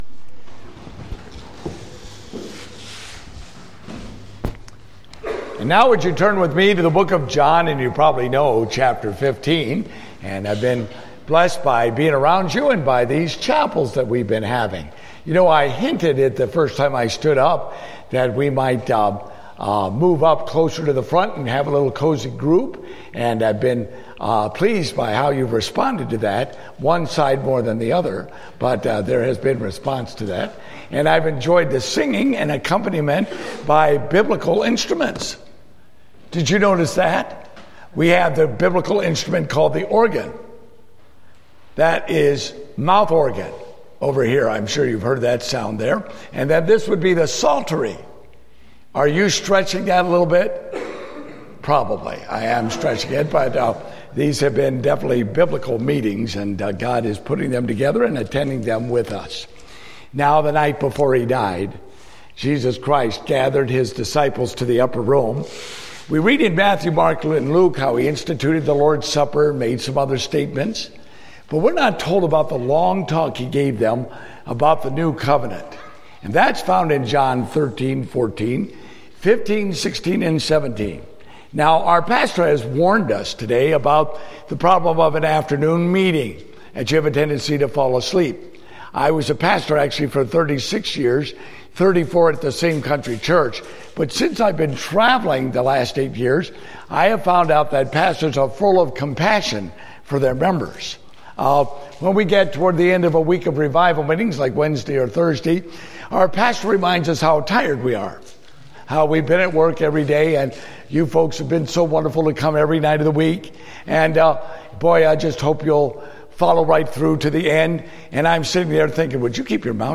Date: August 21, 2015 (Family Camp)